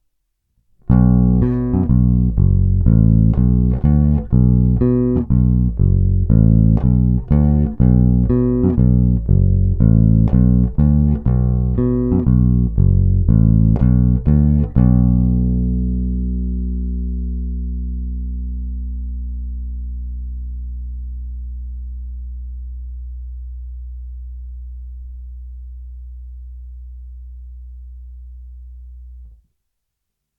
Zvuk je typický (olšový) Precision, jak by člověk očekával, žádné překvapení (ať už příjemné či nepříjemné) se nekoná. Opět jsem provedl "povinné" nahrávky, které jsou nabrané rovnou do zvukovky s plně otevřenou tónovou clonou a ponechány bez dalších úprav kromě normalizace hlasitosti.
Pak jsem na ni natáhl již nějaký čas používané niklové klasické roundwound struny též od firmy D'Addario:
Hra u krku